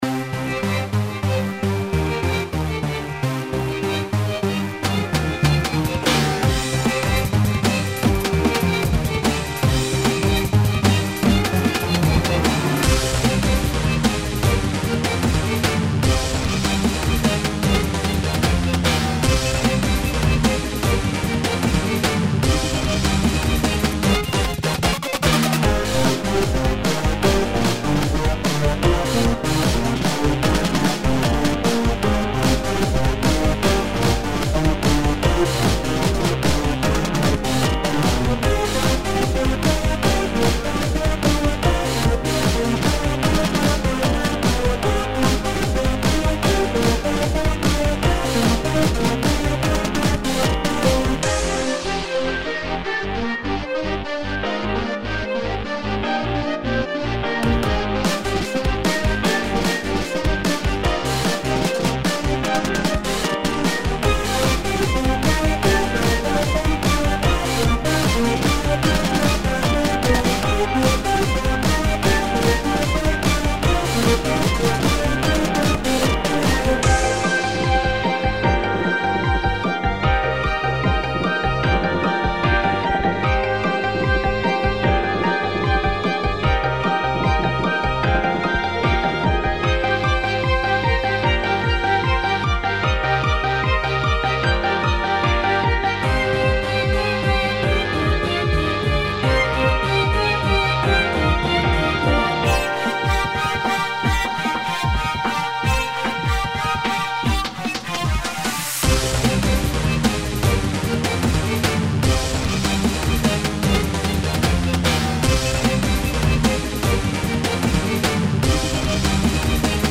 MP3（通常音源の後にループ用を再生することで自然にループできます）